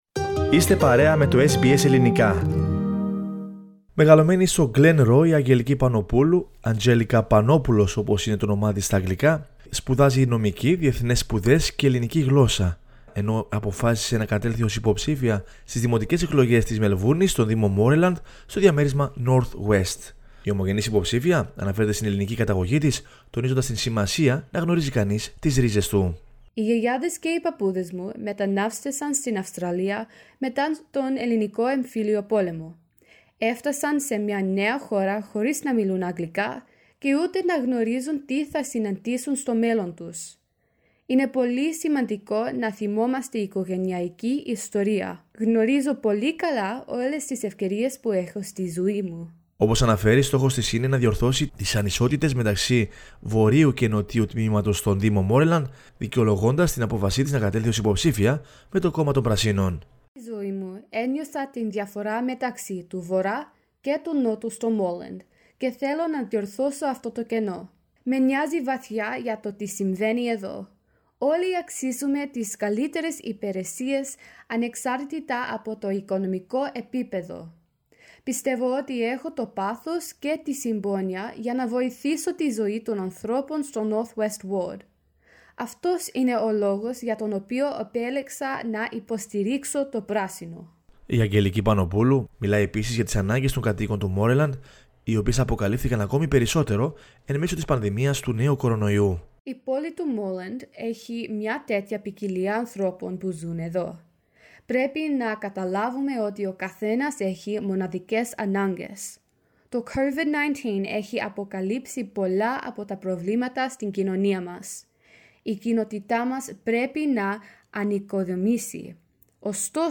Σε συνέντευξή της η ομογενής υποψήφια αναφέρεται στην ελληνική καταγωγή της, τονίζοντας την σημασία να γνωρίζει κανείς τις ρίζες του.